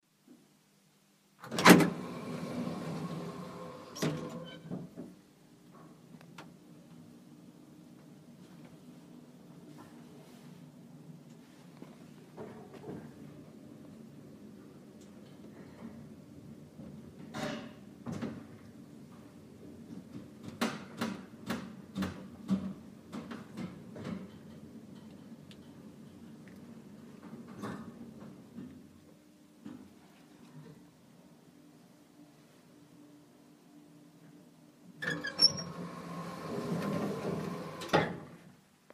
电梯02
描述：在一栋大楼的电梯内上上下下的录音。索尼PCMD50
标签： 建筑 下来 电梯 现场记录 电梯 机械 上涨
声道立体声